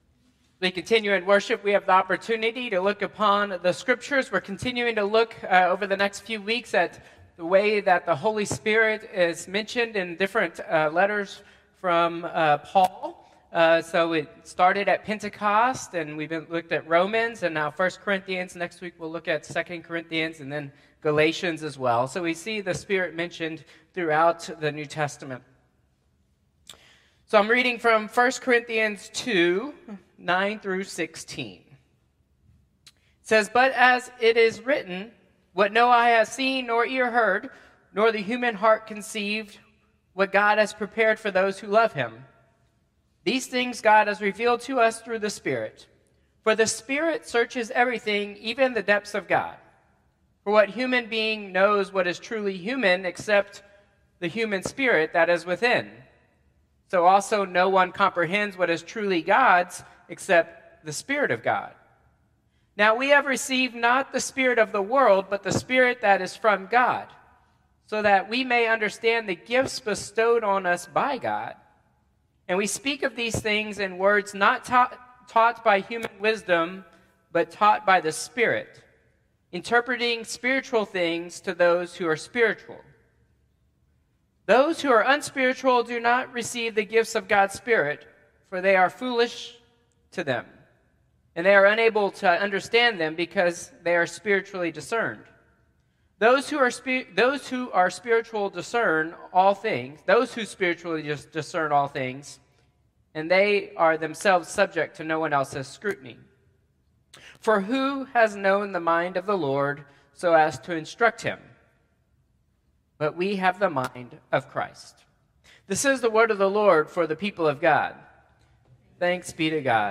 Traditional Service 6/22/2025